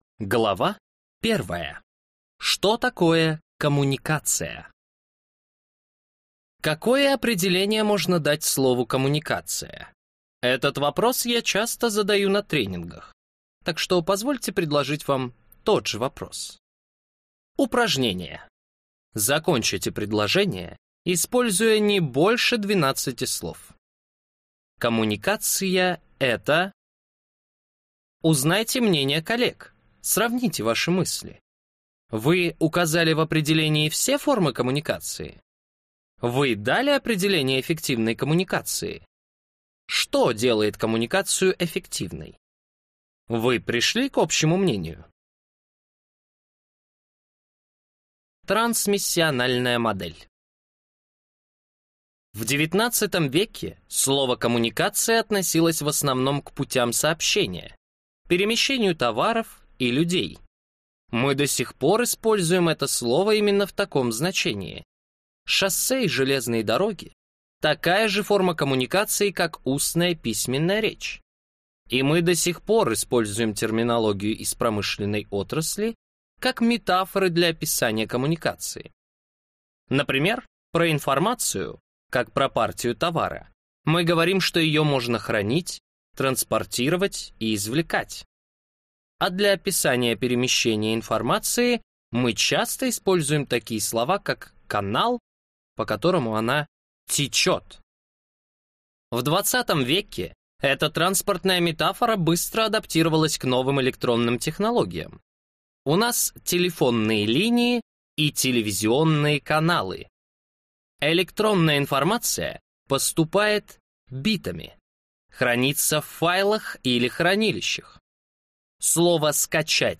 Аудиокнига Как улучшить коммуникативные навыки | Библиотека аудиокниг